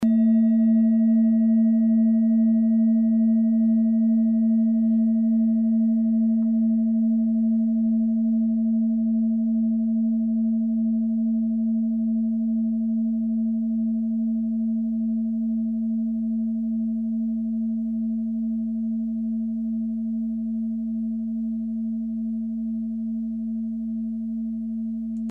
Klangschalen-Typ: Bengalen
Klangschale Nr.3
Gewicht = 1120g
Durchmesser = 18,7cm
(Aufgenommen mit dem Filzklöppel/Gummischlegel)
klangschale-set-1-3.mp3